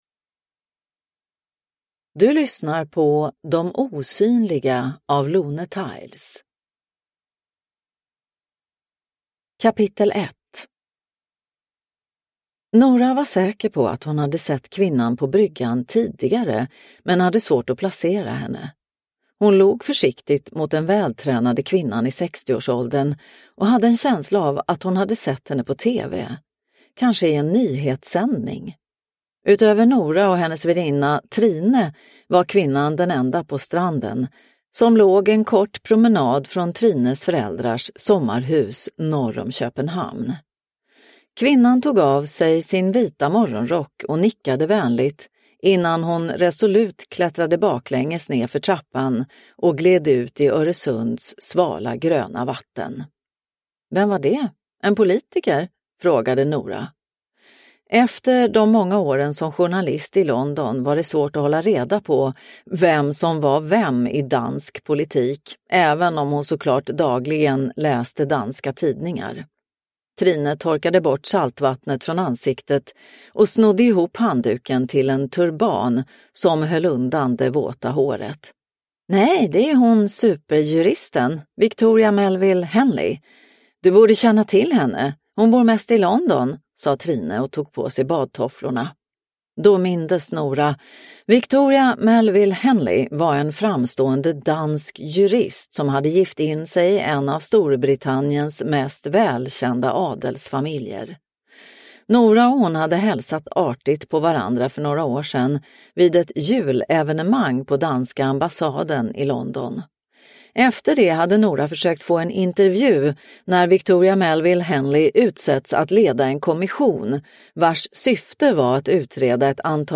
De osynliga – Ljudbok – Laddas ner